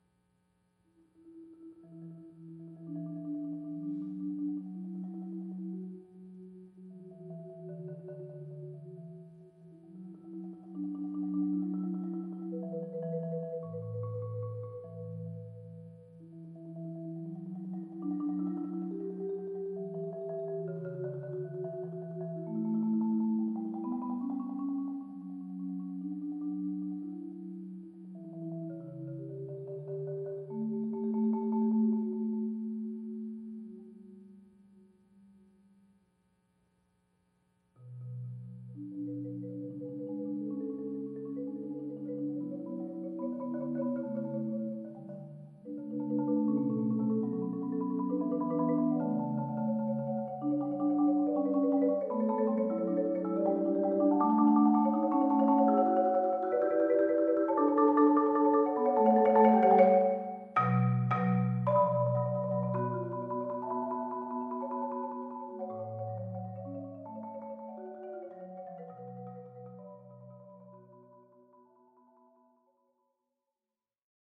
Genre: Marimba (4-mallet)
Marimba (4.5-octave)